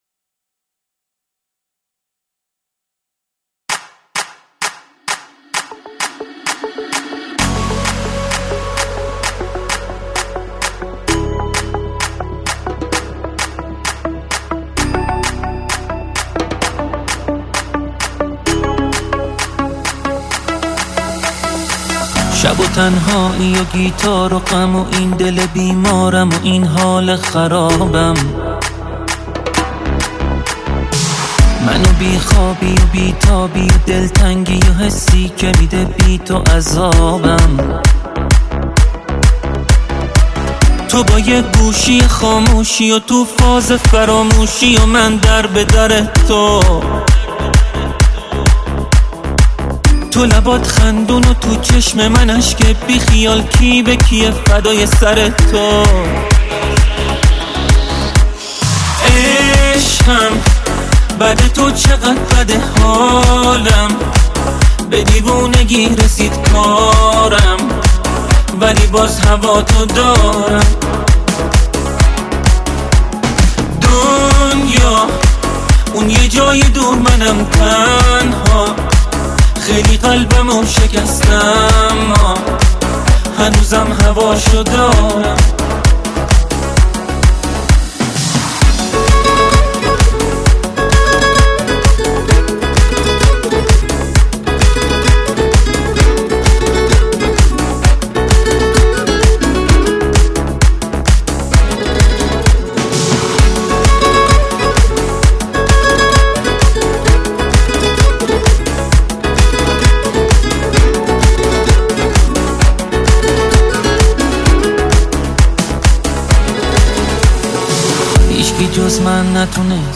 دانلود آهنگ شاد با کیفیت ۱۲۸ MP3 ۴ MB